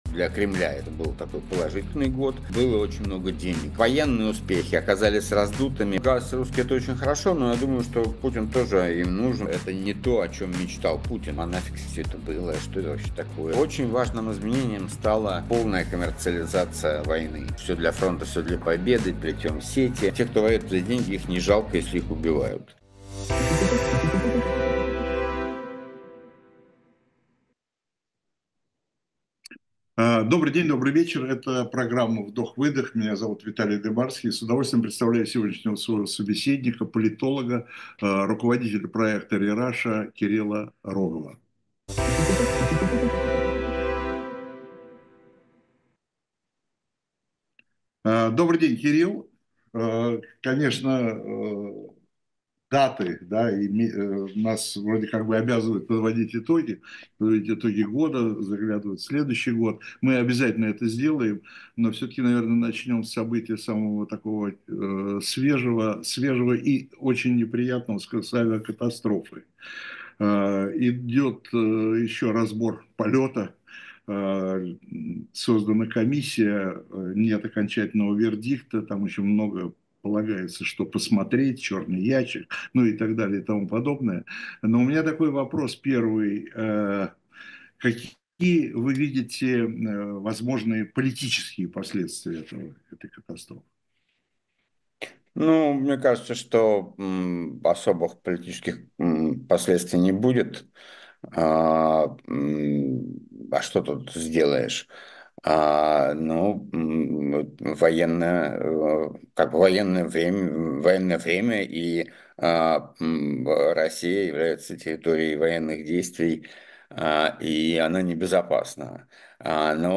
Эфир ведёт Виталий Дымарский